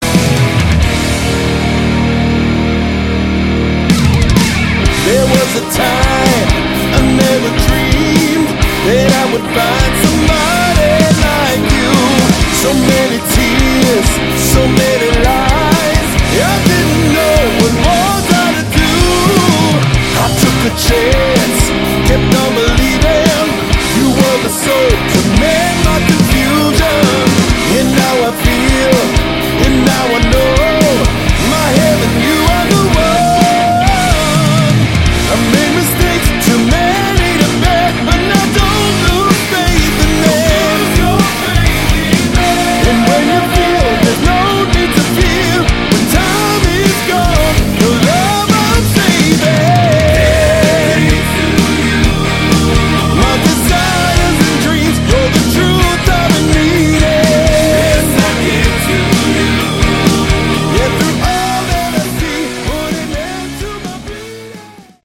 Category: Melodic Rock